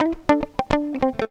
GTR 101 AM.wav